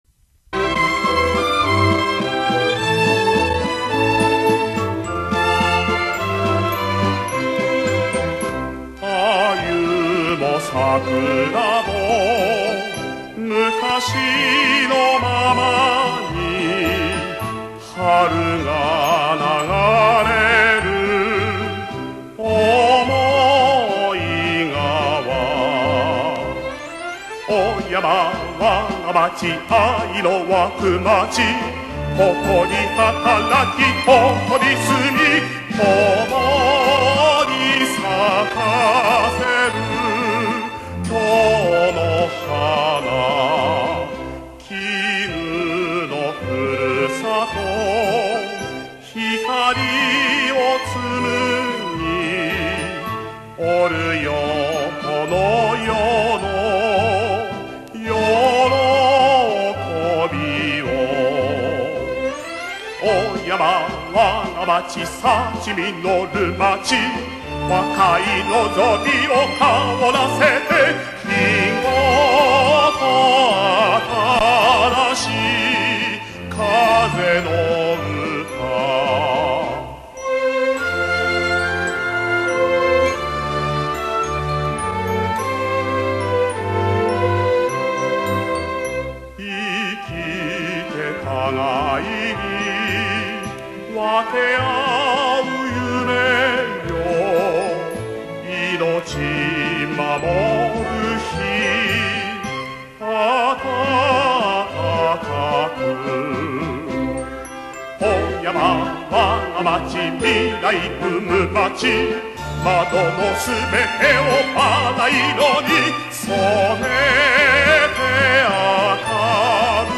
歌詞やメロディは、年齢や性別にとらわれることなく、すべての市民が親しみやすく、わかりやすいものとなっています。